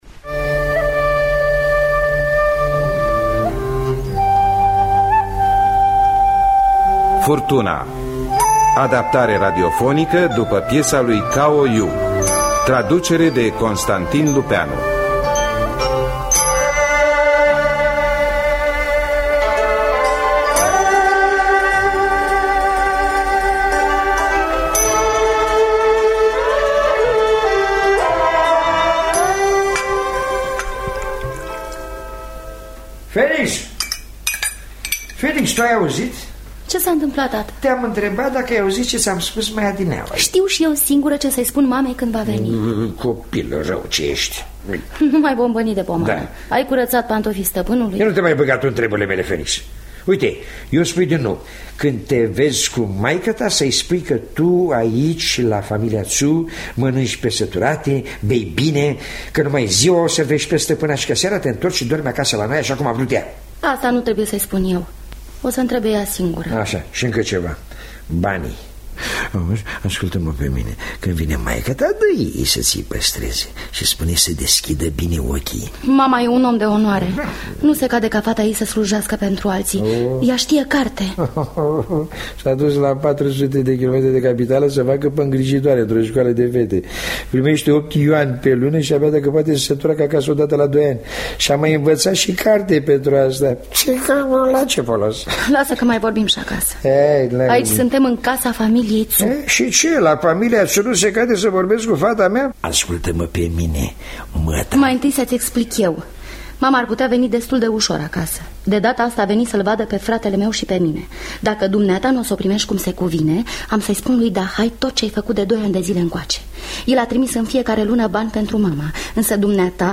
Furtuna de Cao Yu – Teatru Radiofonic Online